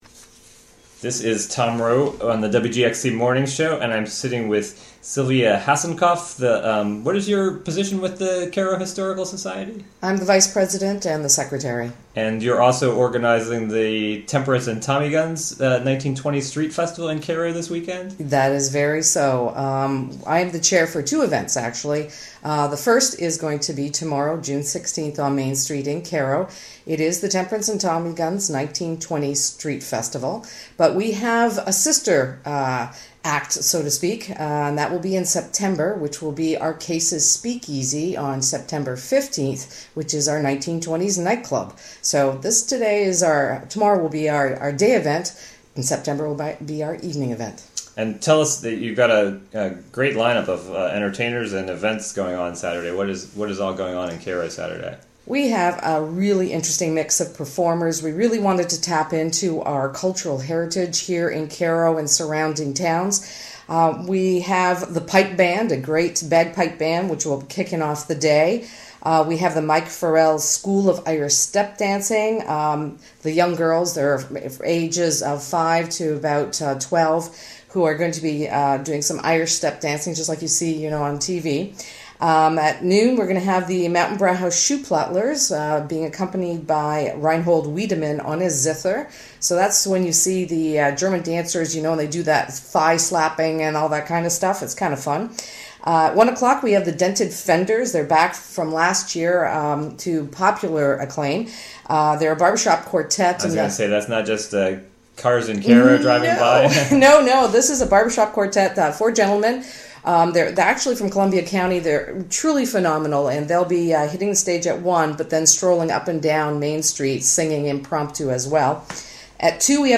Interview from "WGXC Morning Show."